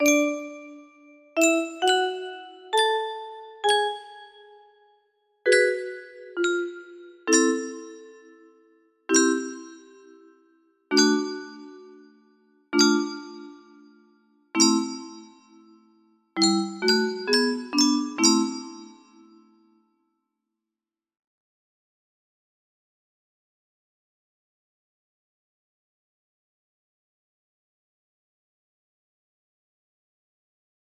57-62 music box melody